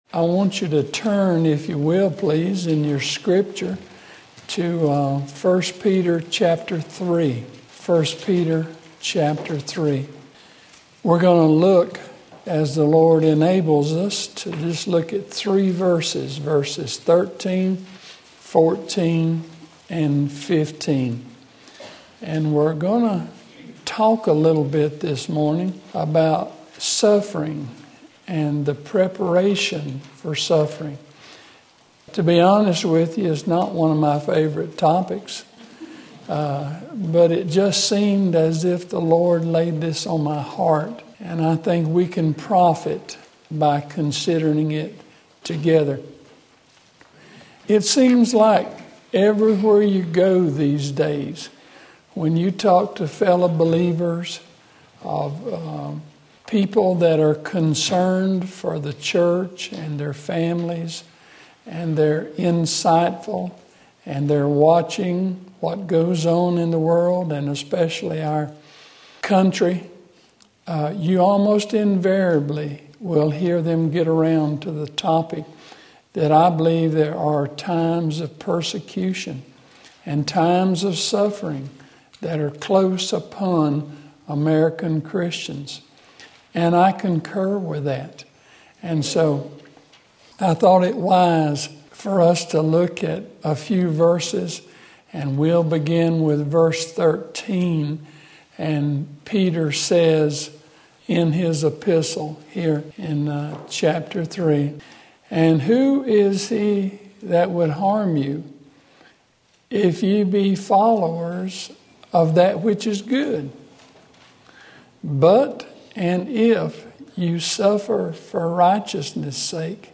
Sermon Link
13-15 Sunday Morning Service